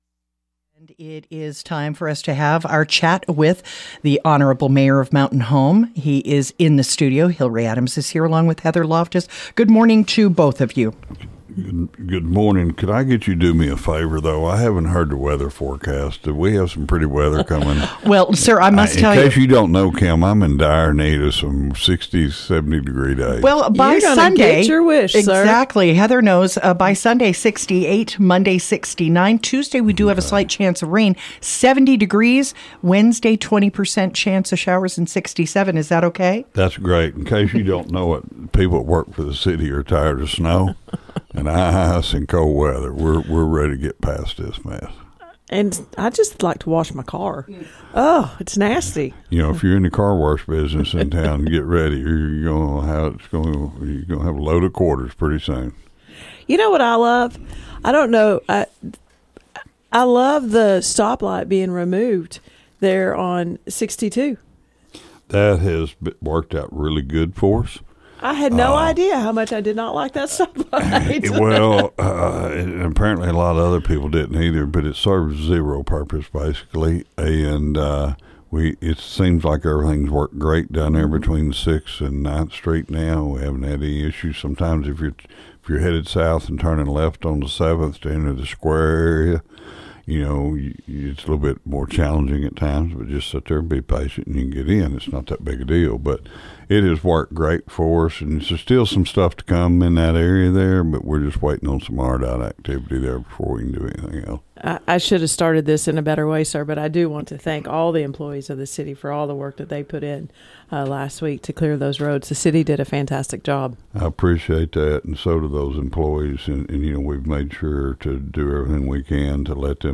During his monthly radio visit on KTLO FM on Thursday morning Mountain Home Mayor Hillrey Adams took time to praise his crews as well as discuss ongoing projects in the city.